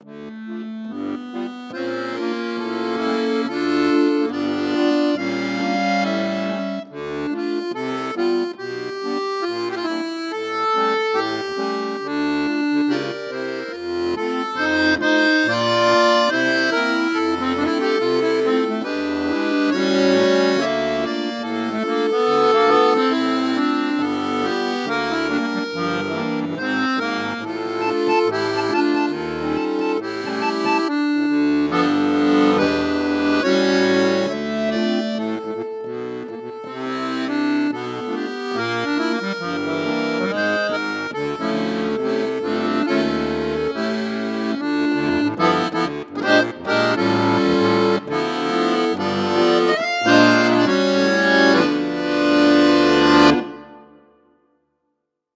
Button Accordion
Solo
Russian concert bayan, fully sampled
• 44.1 kHz \ 24 bit \ Stereo